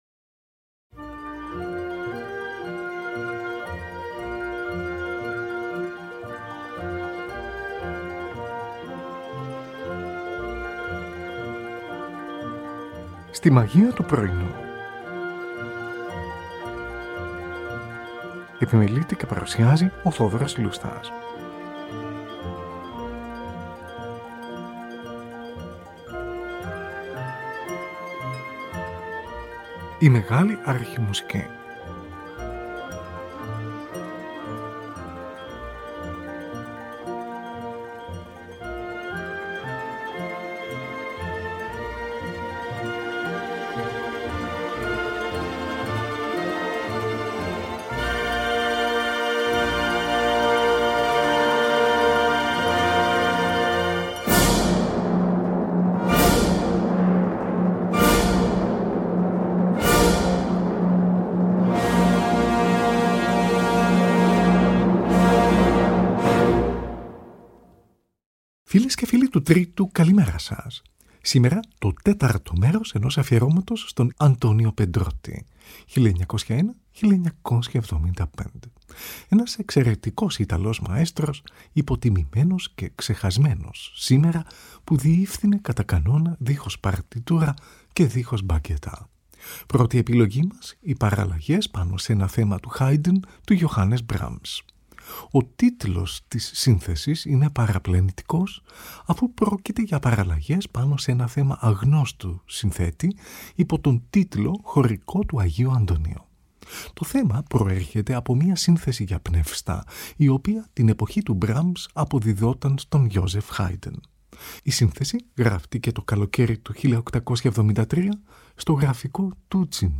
πιανίστας